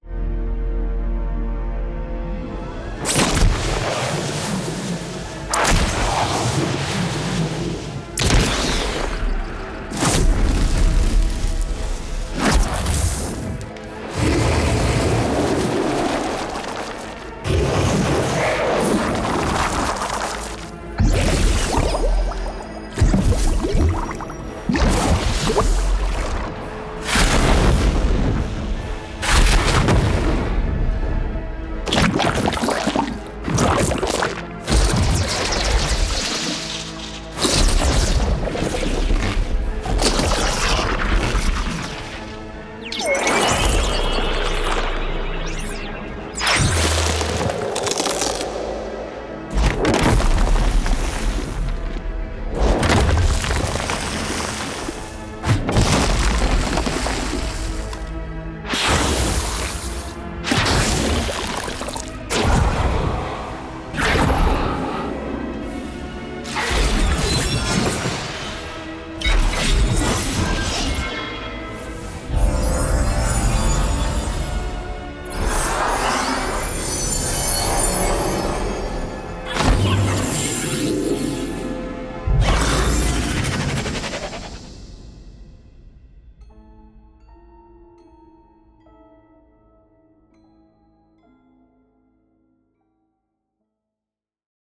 这一次，我们扩展了元素和主题范围，提供了 405 种专业制作的音效，它们都经过全面分类，即刻可用。
毒药、泥浆、岩石
以及奥术、黑暗、怪物和其他神秘类型的法术
每个类别都组织在独立的文件夹中，每个法术都有多种变体，让您可以灵活地为每个场景选择完美的音效。